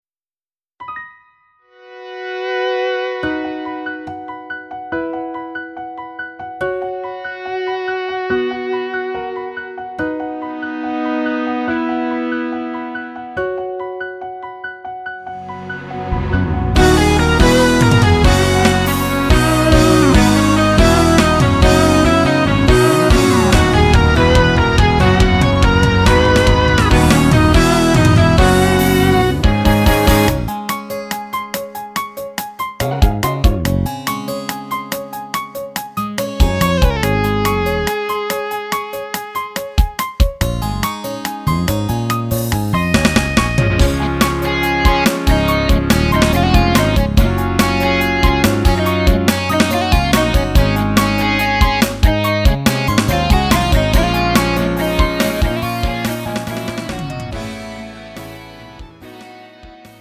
음정 -1키
장르 가요 구분 Lite MR